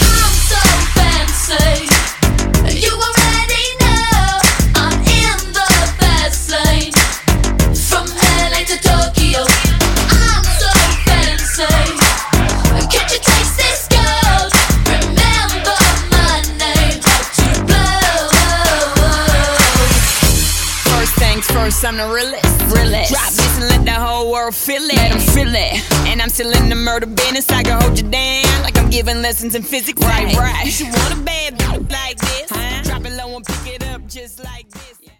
Genre: RE-DRUM Version: Clean BPM: 128 Time